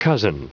Prononciation du mot cozen en anglais (fichier audio)
Prononciation du mot : cozen